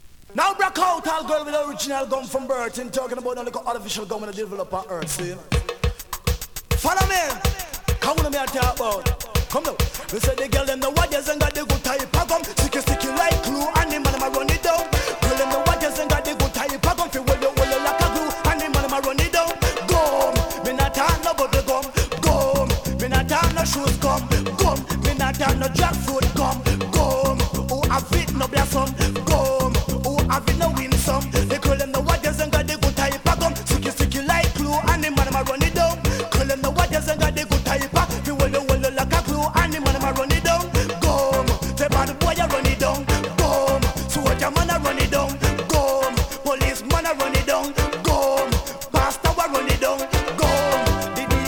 DANCEHALL!!
スリキズ、ノイズ比較的少なめで